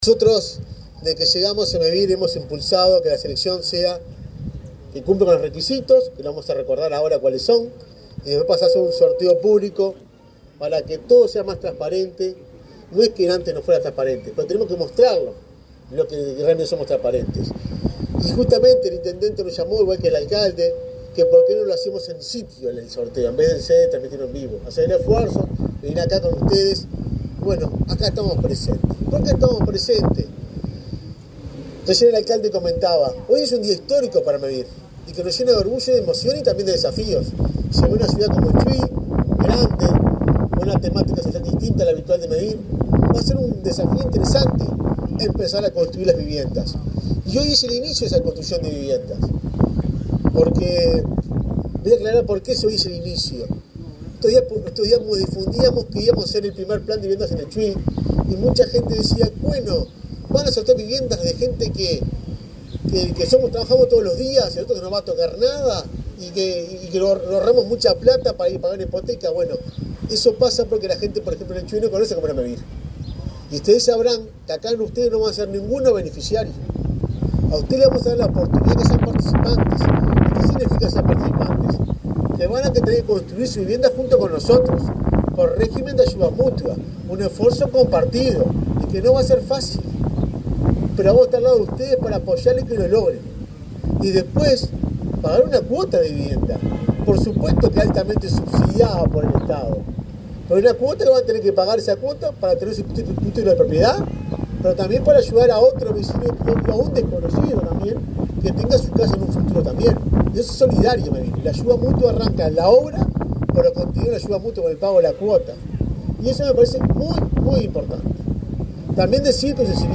El presidente de Mevir, Juan Pablo Delgado, participó en el acto de sorteo de aspirantes a un plan de viviendas nucleadas en la localidad de Chuy,